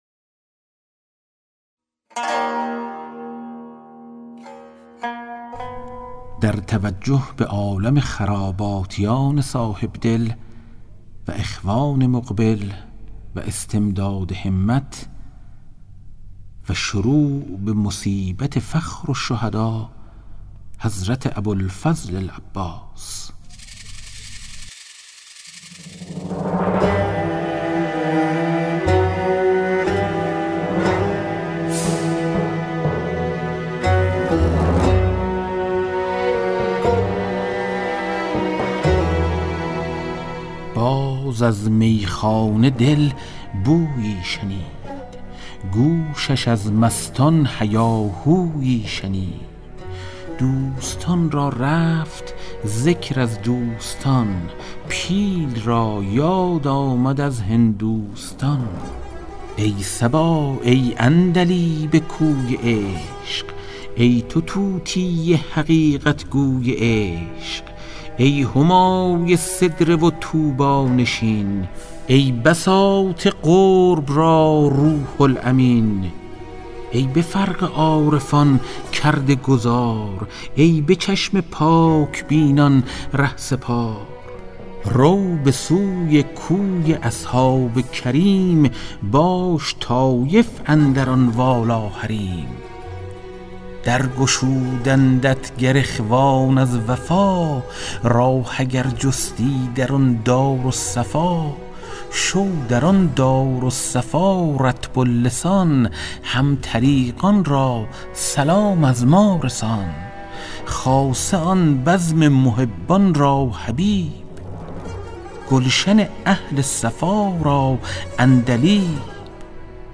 کتاب صوتی گنجینه‌الاسرار، مثنوی عرفانی و حماسی در روایت حادثه عاشورا است که برای اولین‌بار و به‌صورت کامل در بیش از 40 قطعه در فایلی صوتی در اختیار دوستداران ادبیات عاشورایی قرار گرفته است.